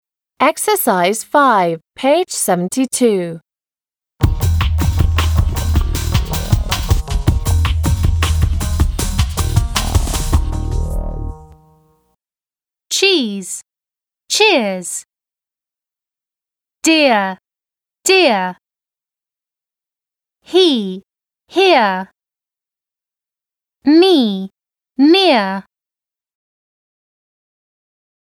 Ex.5,p.72.mp3 (4 MB) К этому заданию есть аудио скриншот условия Pronunciation /i:/ - /iə/ Reading Rules e, ee, ea – /i:/ s ee , s ea ea, ee + r – /iə/ f ea r 6 Copy the table.